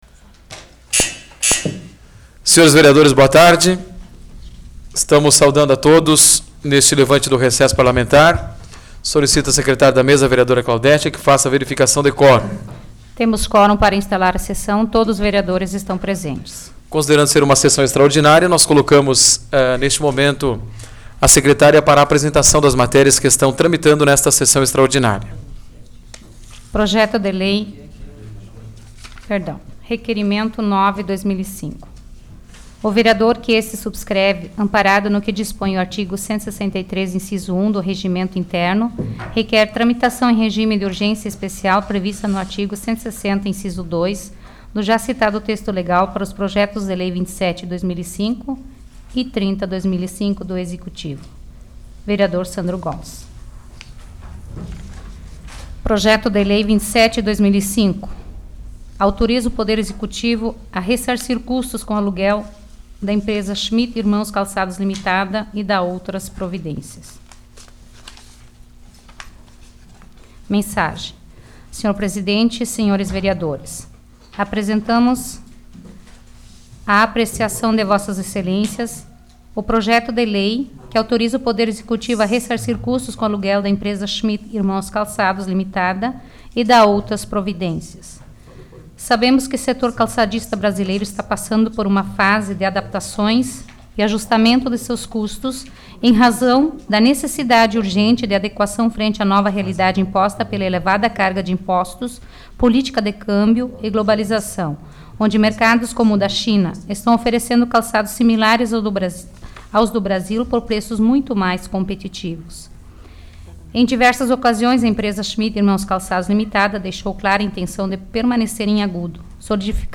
Áudio da 9ª Sessão Plenária Extraordinária da 12ª Legislatura, de 14 de julho de 2005